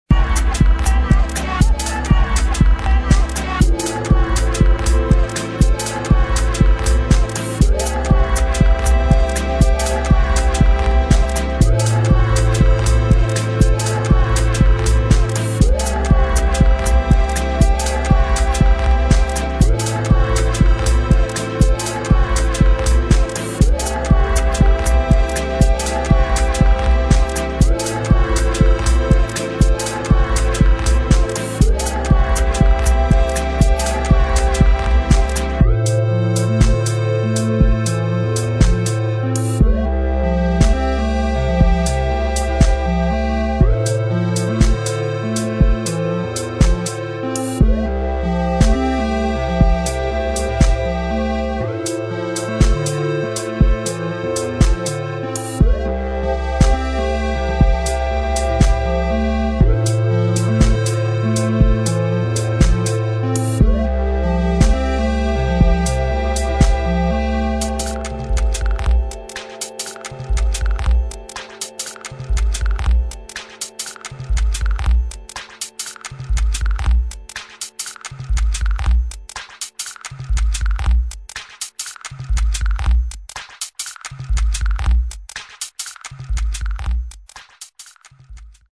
[ DEEP HOUSE / ACID / TECHNO ]